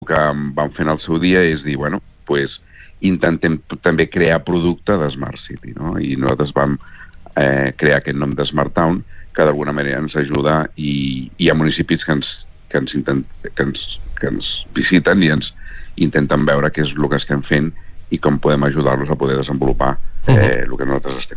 El regidor de promoció econòmica de Calonge i Sant Antoni, Arturo Pradas, ens va visitar al Supermatí de dimecres passat per detallar-nos les jornades Sustainable Mobility for a Safe & Smart Town que se celebraran aquest cap de setmana.